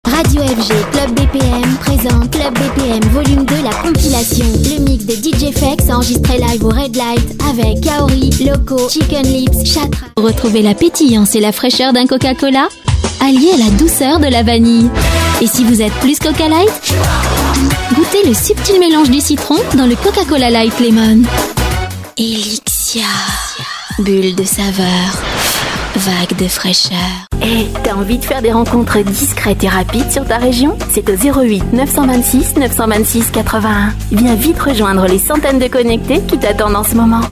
Sprecherin französisch
Kein Dialekt
Sprechprobe: Industrie (Muttersprache):
female french voice over artist